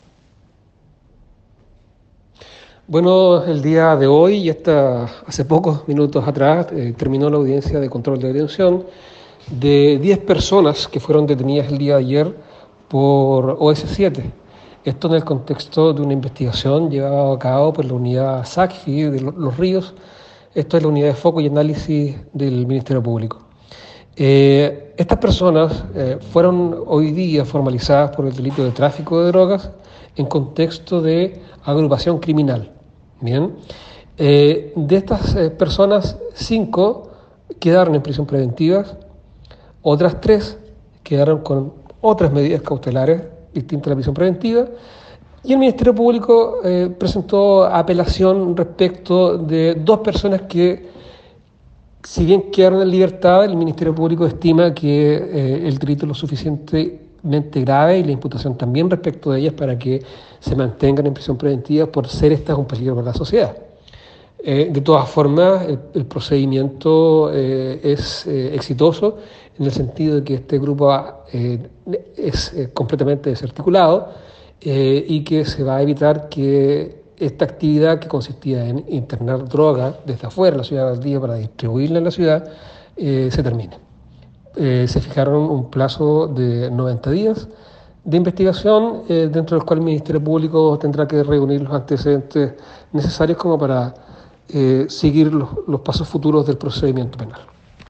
Fiscal Carlos Bahamondes…